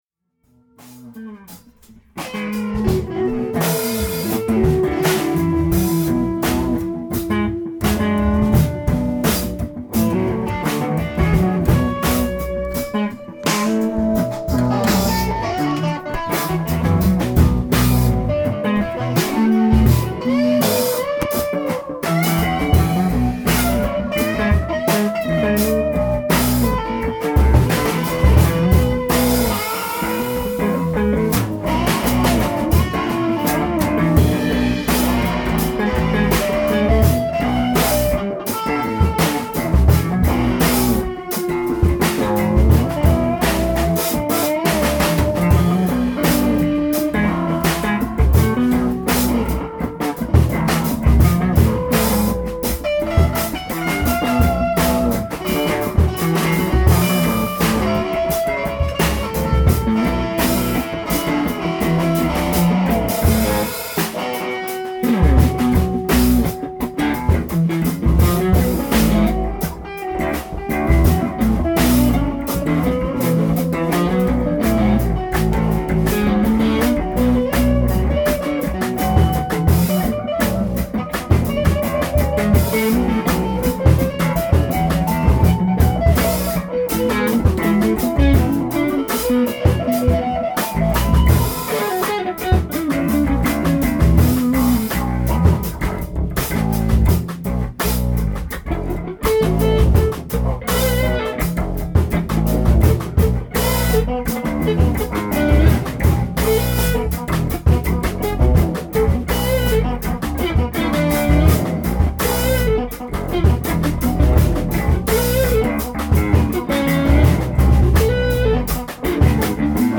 Epic Weekend (warmup jam) (11 megs)
Tent Flag (jam after Under The Tent) (6 megs)